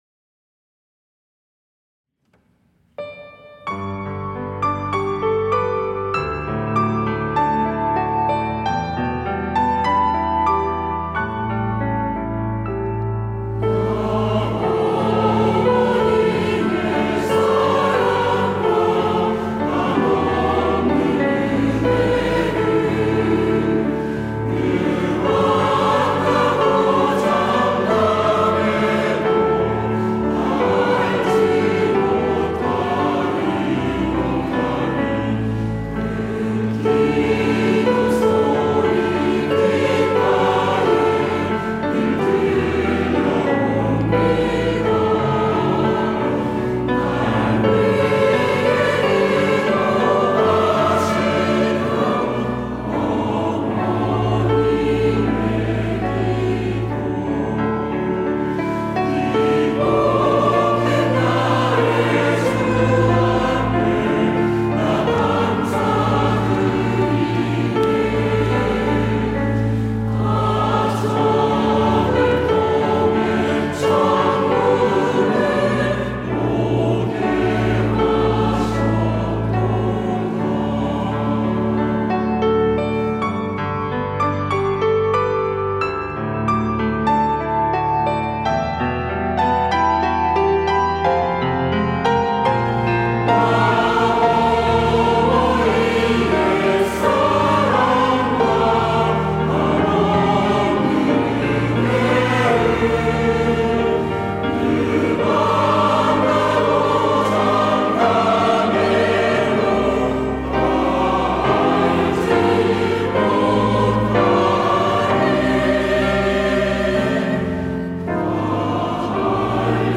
시온(주일1부) - 어머니 날을 위한 기도
찬양대